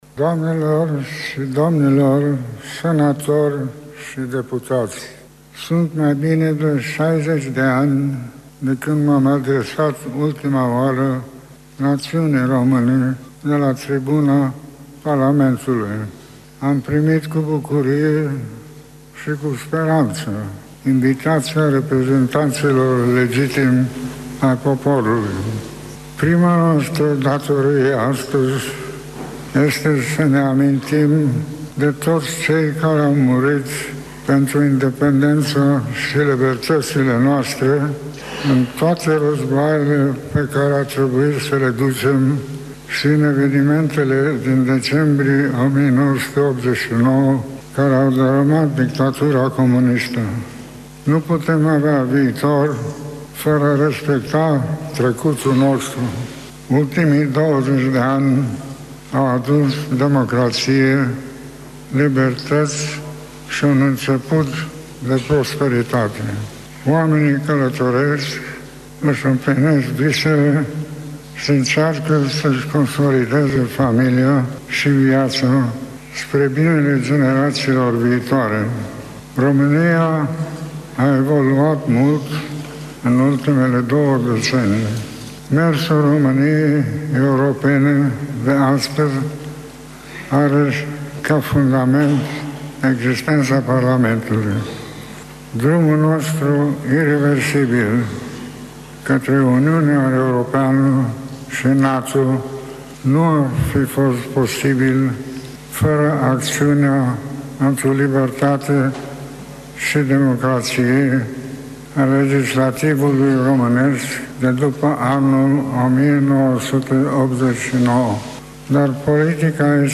(AUDIO/VIDEO) Ultimul discurs al Regelui Mihai I al României în Parlament - Radio Iaşi – Cel mai ascultat radio regional - știri, muzică și evenimente
Discursul-Regelui-Mihai-I-in-Parlamentul-Romaniei-.mp3